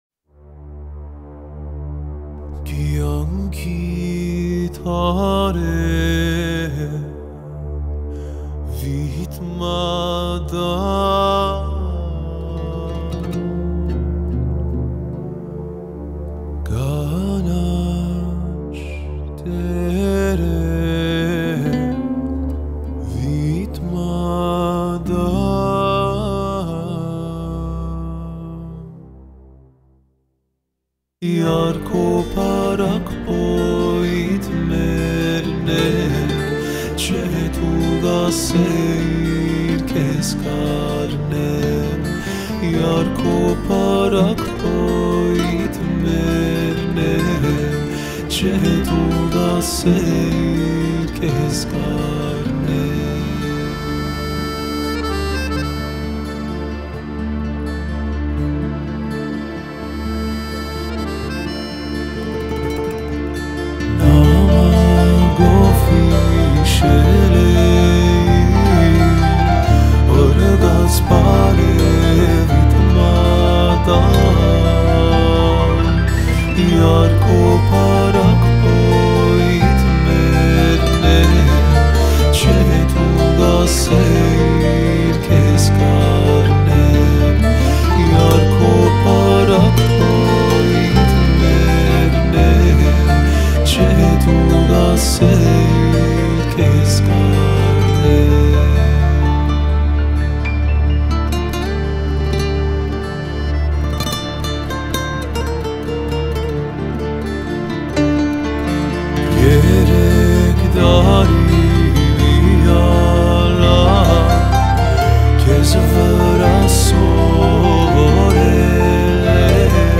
love ballad